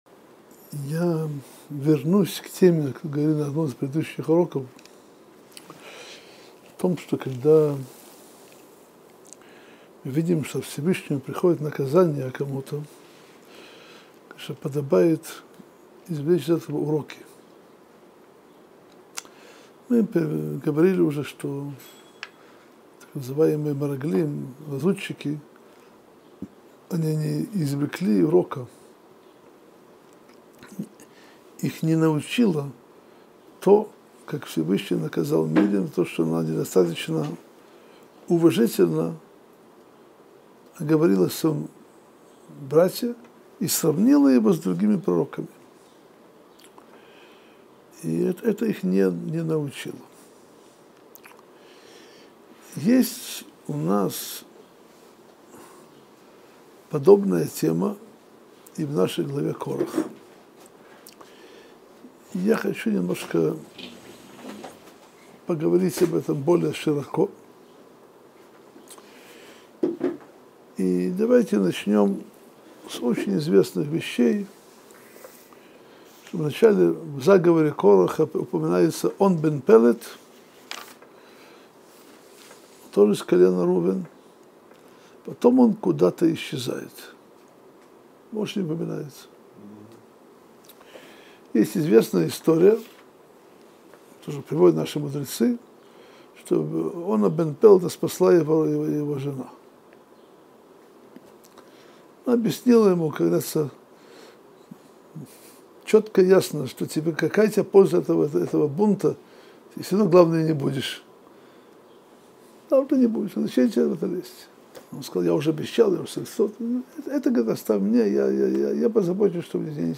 Содержание урока: Кто спас Она бен Пеледа? Корах был праведником или вероотступником?